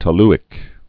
(tə-lĭk)